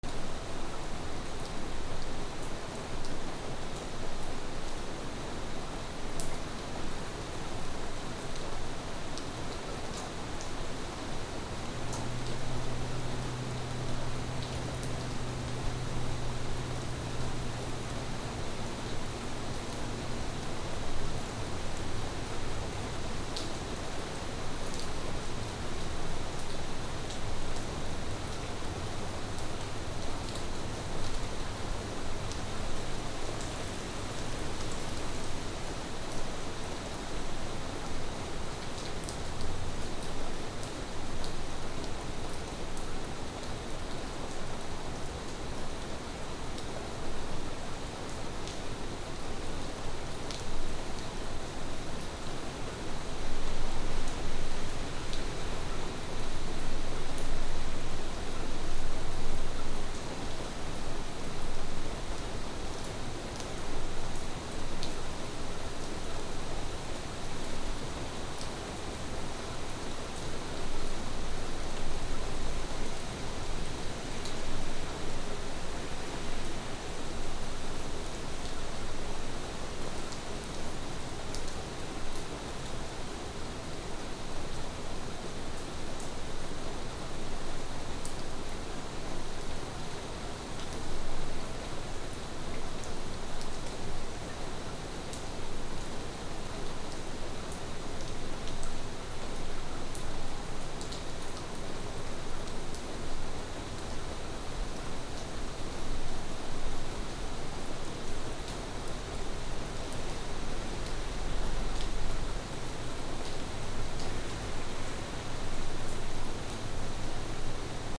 / A｜環境音(天候) / A-45 ｜台風 嵐
台風8
NT4 原音あり